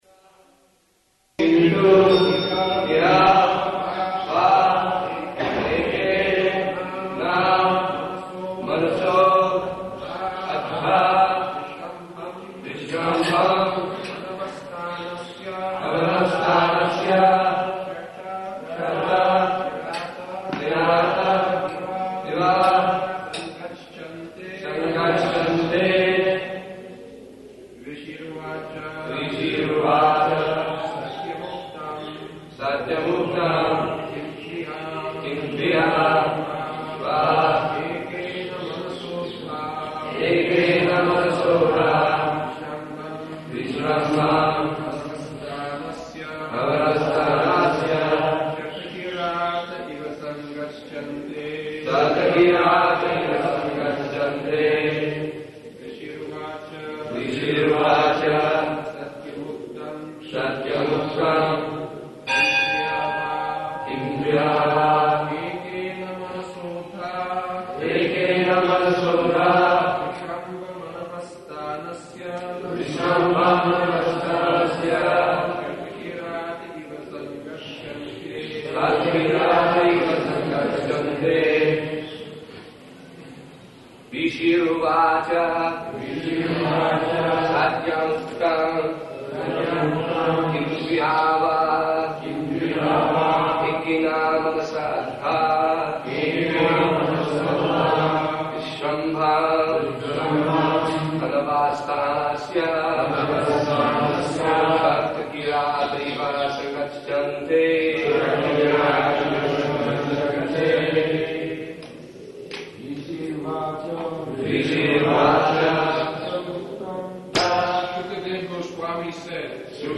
[Chants verse]